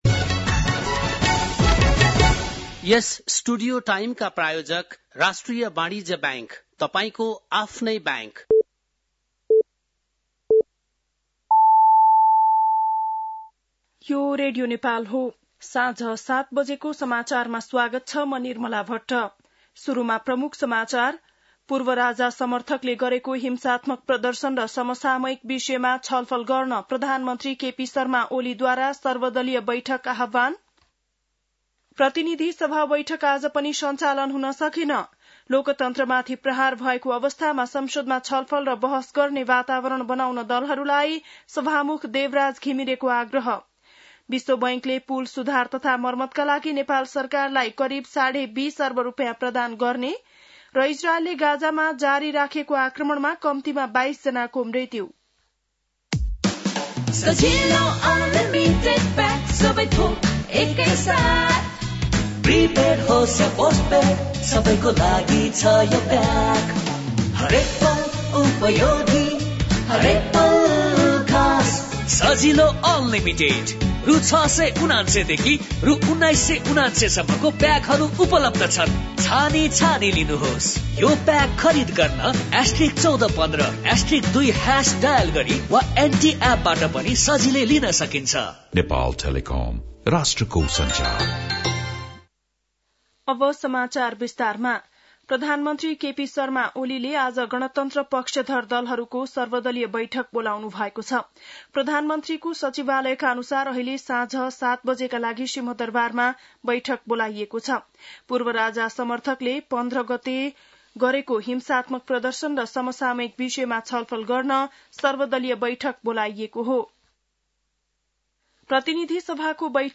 बेलुकी ७ बजेको नेपाली समाचार : १७ चैत , २०८१
7-pm-nepali-news-12-17.mp3